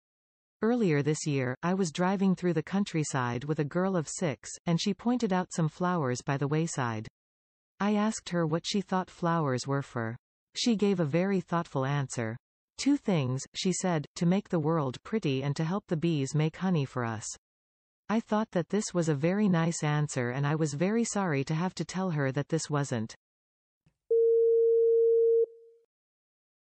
The audio?s final phrase is replaced by a beep sound.
Sample: You will hear a recording of a lecture. At the end of the recording the last word or group of words has been replaced by a beep. Select the correct option to complete the recording.